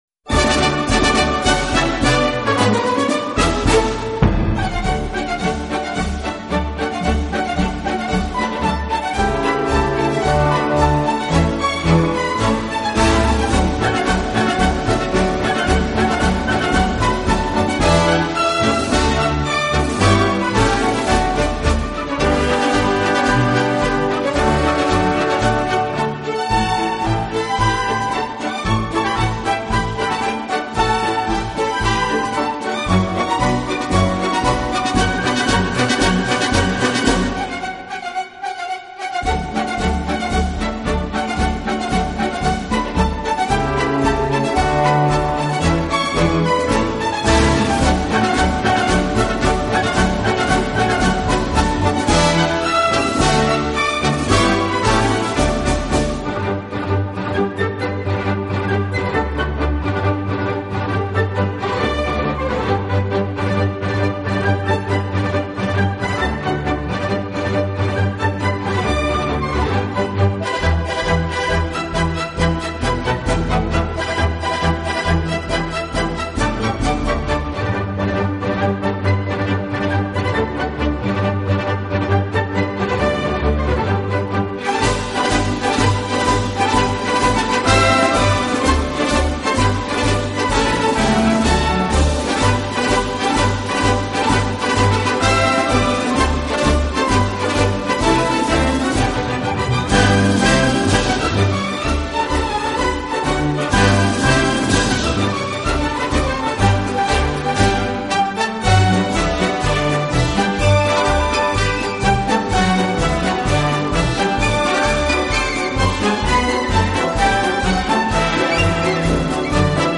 【优美小提琴】
的演出方式，自己边拉小提琴边指挥乐队。